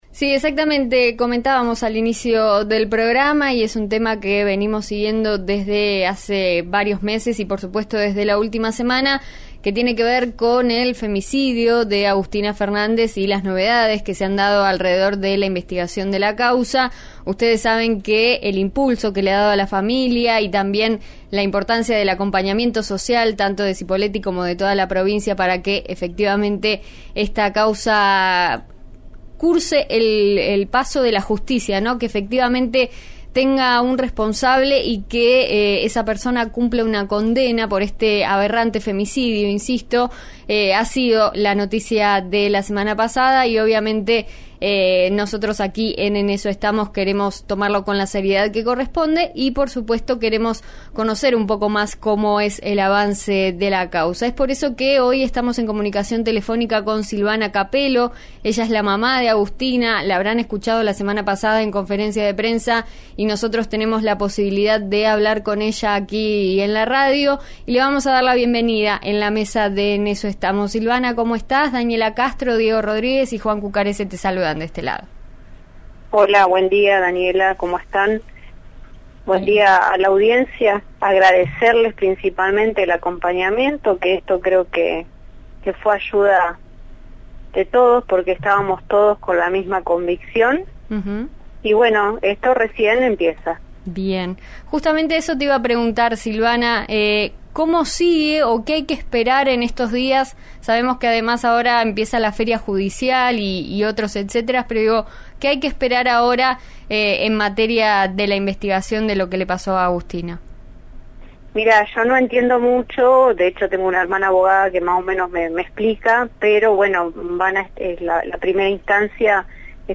dialogó con RN Radio sobre cómo continuará la causa y cuáles son sus expectativas en el juicio.